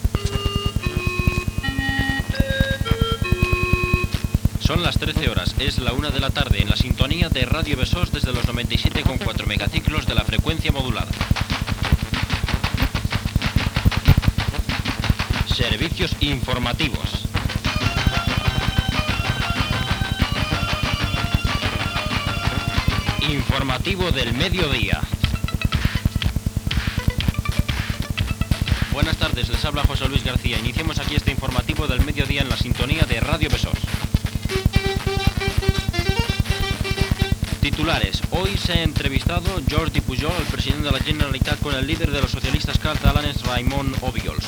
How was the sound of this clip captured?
Hora, identificació i inici de l'"Informativo del mediodía". FM